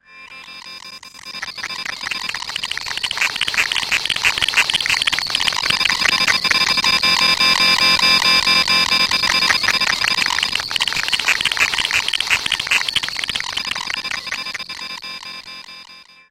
На этой странице собрана коллекция звуков цифровых глюков, сбоев и помех.
Звук сломанной программы лагает